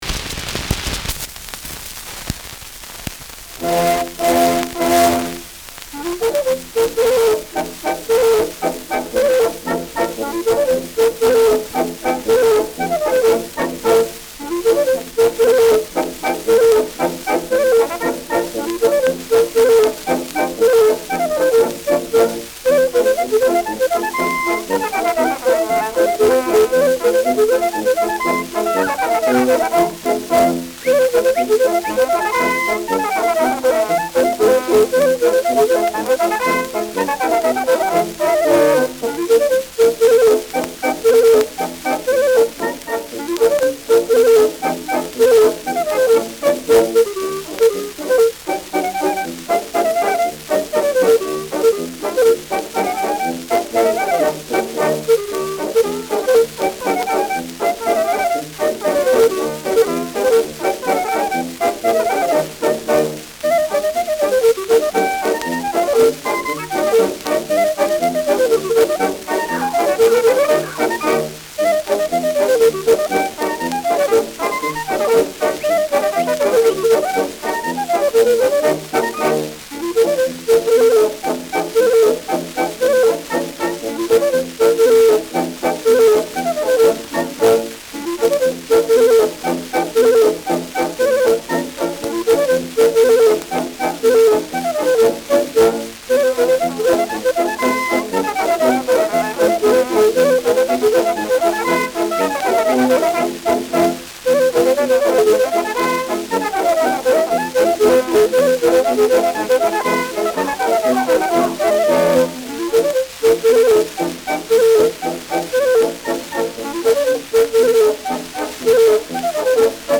Der Seppl von Gaging : Klarinettenschottisch
Schellackplatte
Abgespielt : Vereinzelt leichtes Knacken : Erhöhter Klirrfaktor
Militärmusik des k.b. 14. Infanterie-Regiments, Nürnberg (Interpretation)
[Nürnberg] (Aufnahmeort)